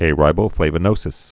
(ā-rībō-flāvə-nōsĭs, -bə-)